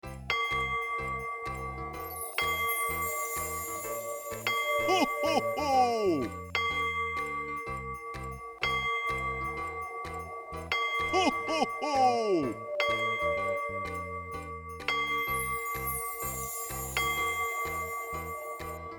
cuckoo-clock-09.wav